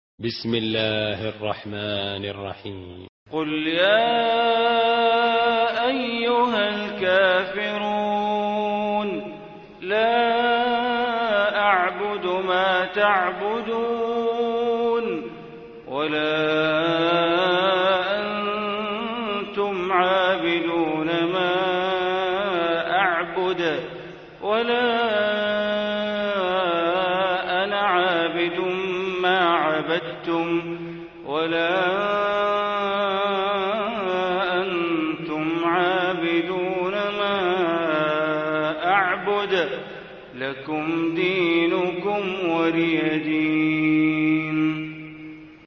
Surah Kafirun Recitation by Sheikh Bandar Baleela
Surah Al-Kafirun, listen online mp3 tilawat / recitation in Arabic in the beautiful voice of Sheikh Bandar Baleela.